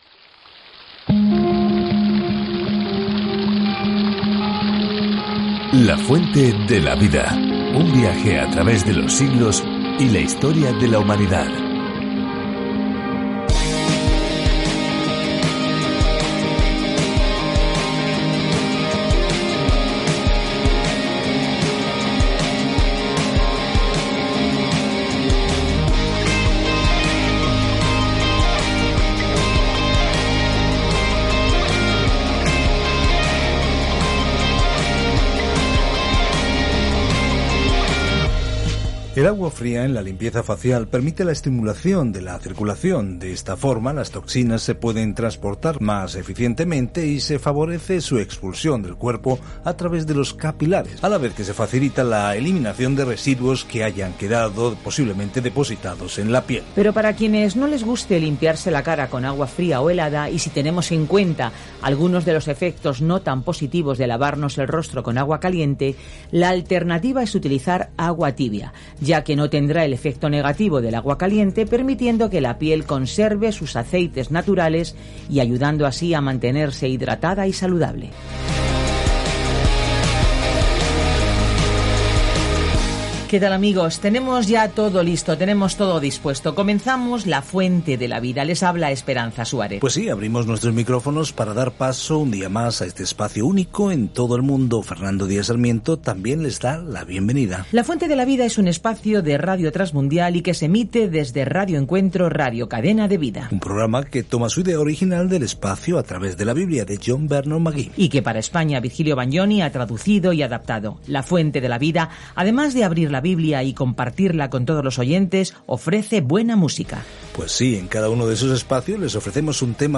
Viaja diariamente a través de 1 Juan mientras escuchas el estudio en audio y lees versículos seleccionados de la palabra de Dios.